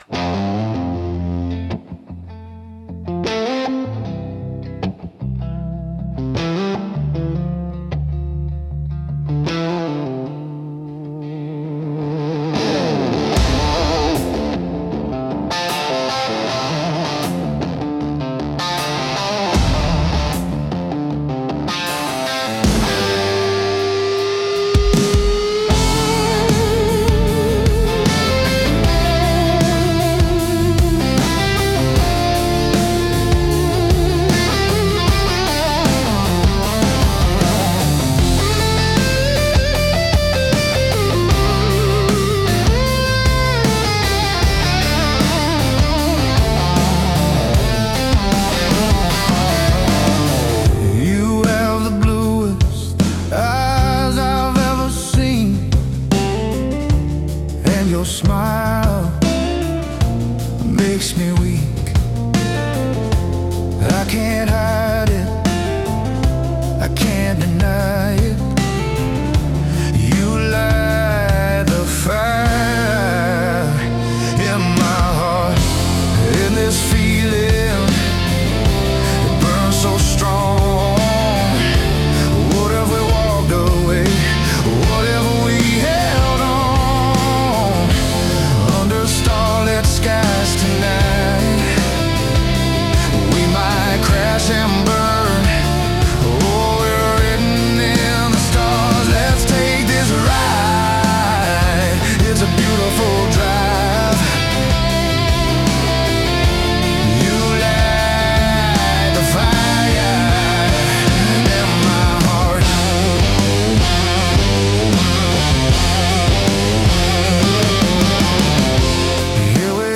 raw, anthemic energy
The core of this song is its chorus.